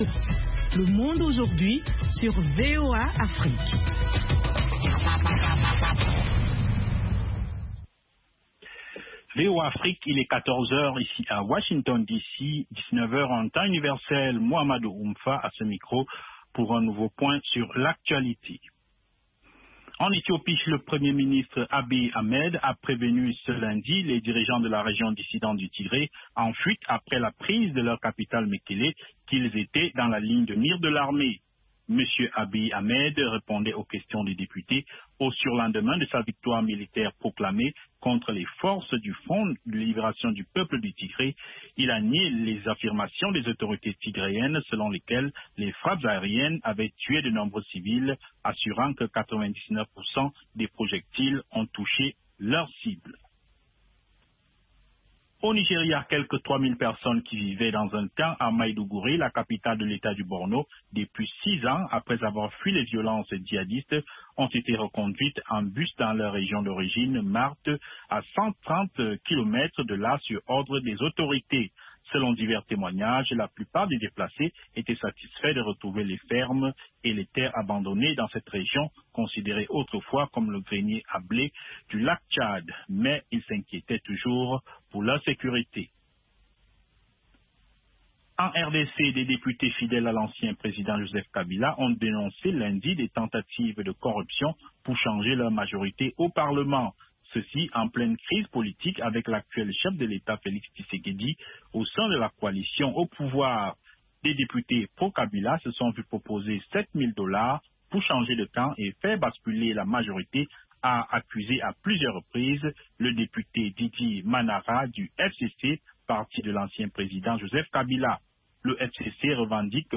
3 min Newscast